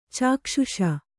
♪ cākṣuṣa